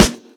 Snares
CGS_SNR.wav